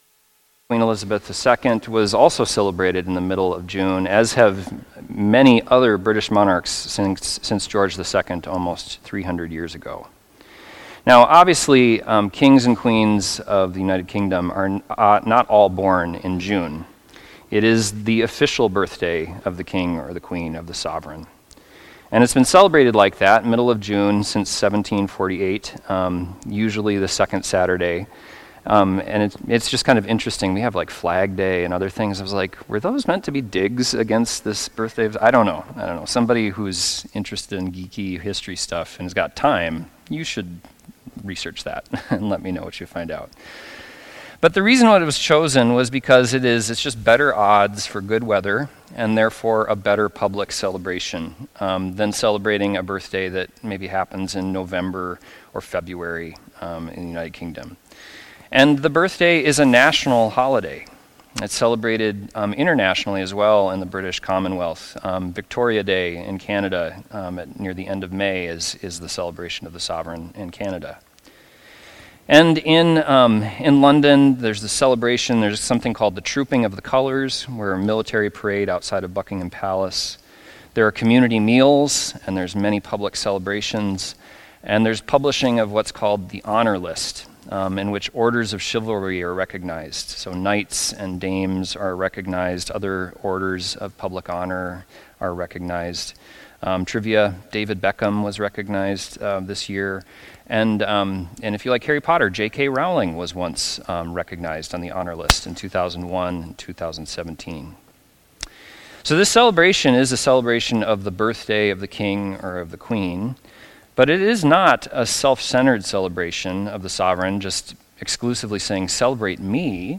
Sunday Worship–June 29, 2025